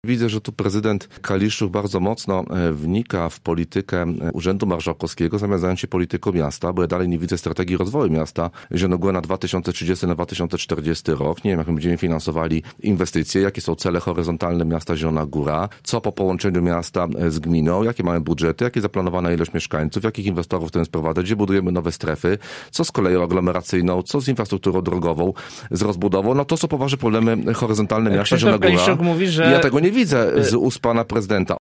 Marcin Pabierowski był gościem Rozmowy po 9.